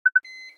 Keycard_Denied.wav